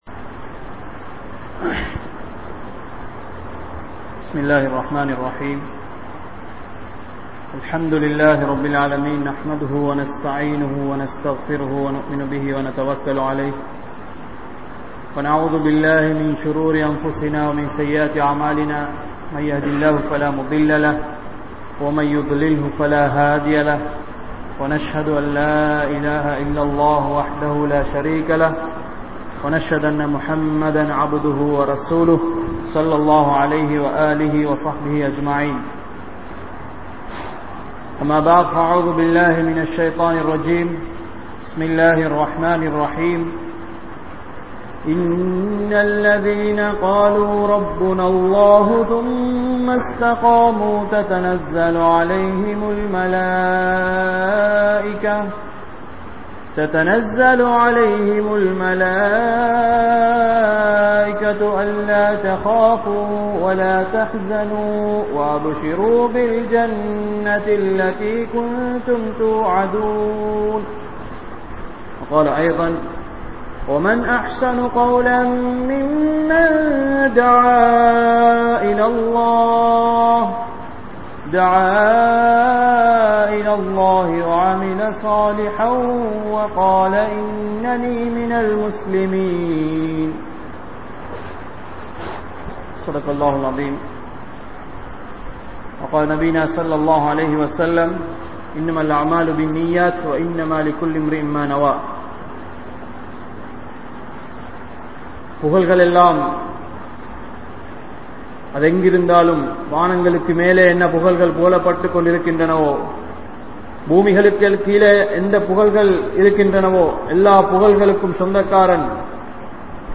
Muslimin Panpuhal (முஸ்லிமின் பண்புகள்) | Audio Bayans | All Ceylon Muslim Youth Community | Addalaichenai
South Eastern University Jumua Masjith